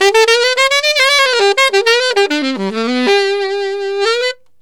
Alto One Shot in G 01.wav